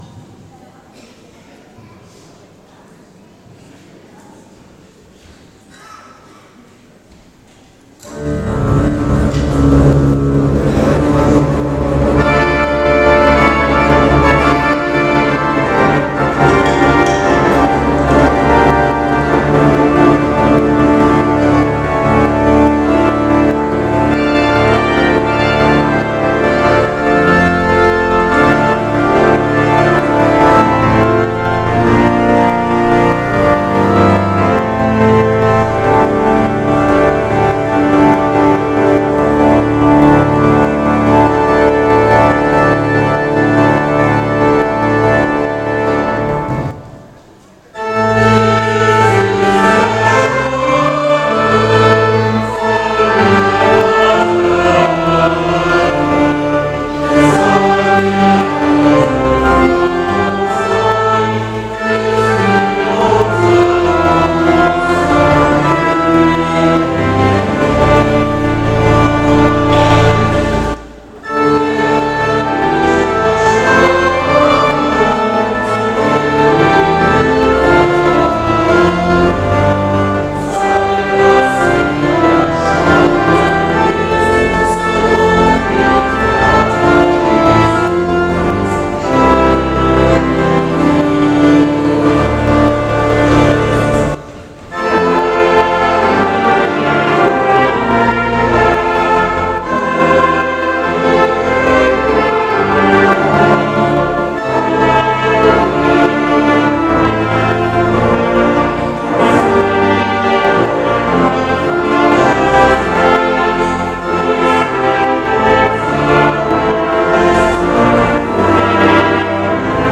Aktuelle Predigt
Gottesdienst vom 25.01.2026 als Audio-Podcast Liebe Gemeinde, herzliche Einladung zum Gottesdienst vom 25. Januar 2026 in der Martinskirche Nierstein als Audio-Podcast.